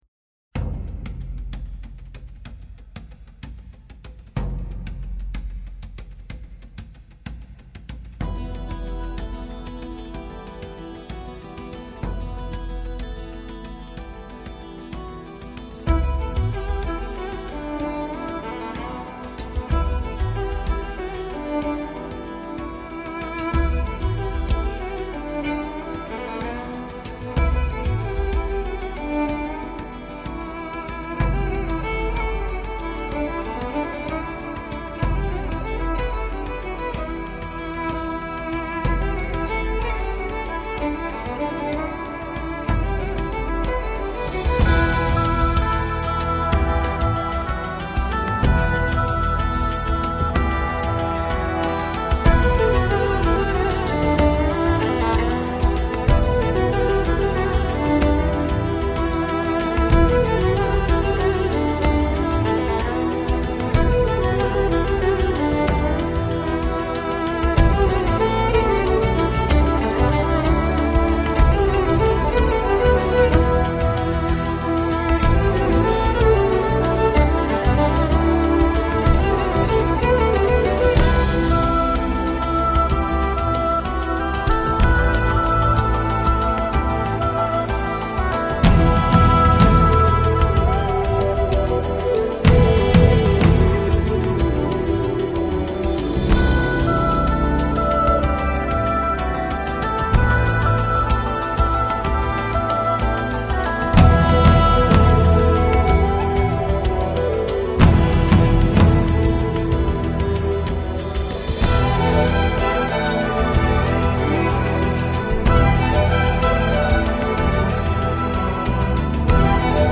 Keltskaja_muzyka-03-spaces.ru_.mp3